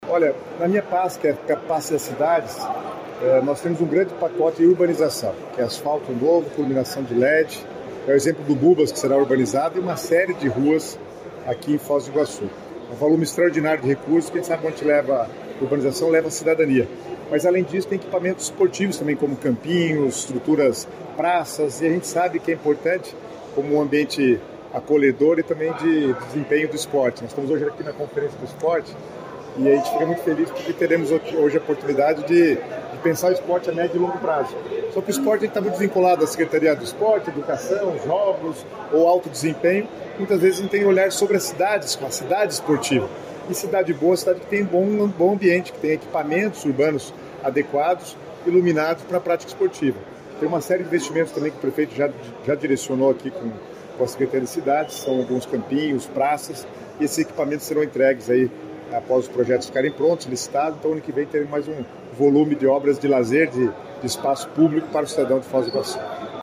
Sonora do secretário das Cidades, Guto Silva, sobre o Plano Decenal do Esporte do Paraná